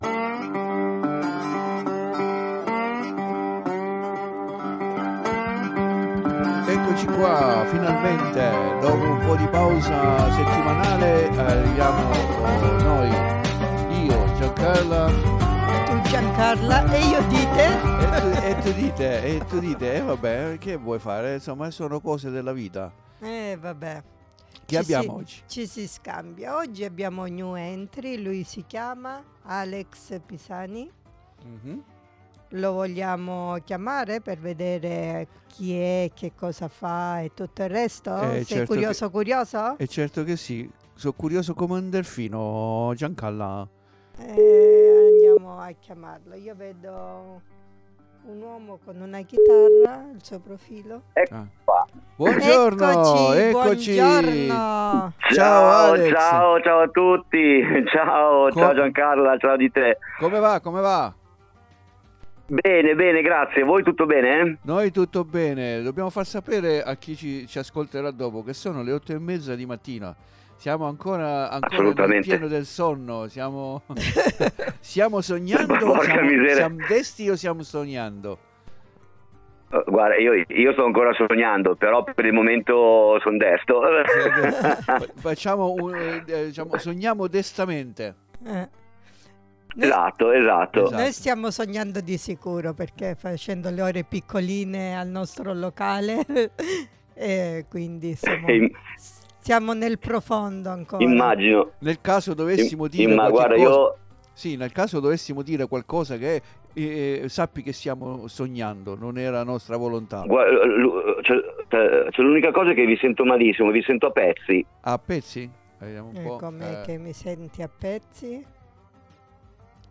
VI INVITO A SENTIRE QUESTA INTERVISTA E VI AUGURO BUON ASCOLTO !